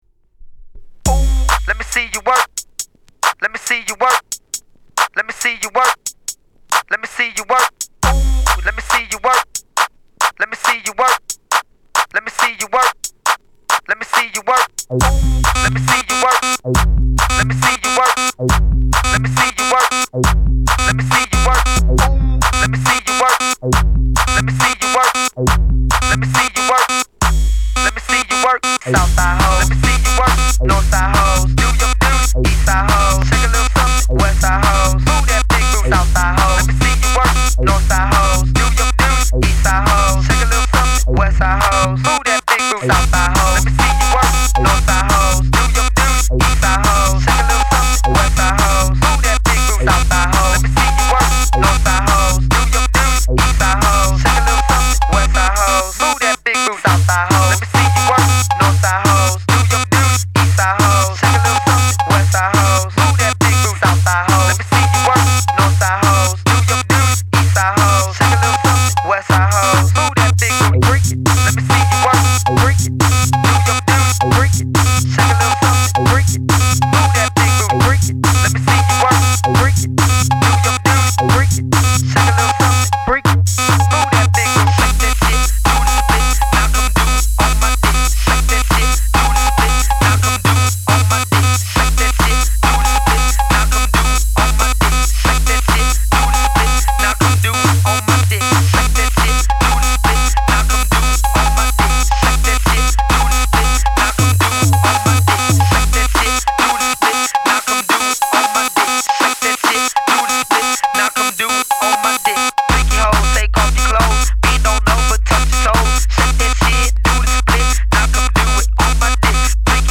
GHETTO HOUSE